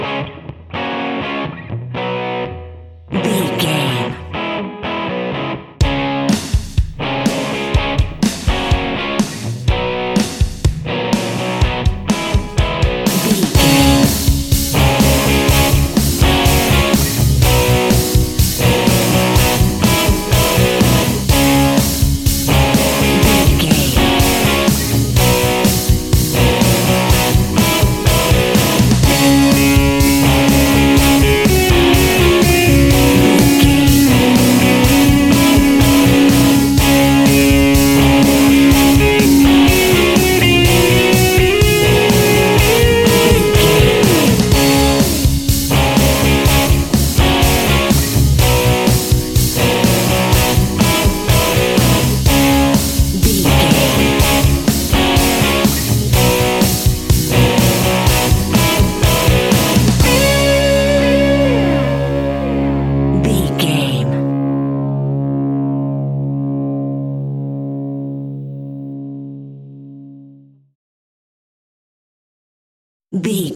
Uplifting
Ionian/Major
D♭
hard rock
blues rock
Rock Bass
Rock Drums
heavy drums
distorted guitars
hammond organ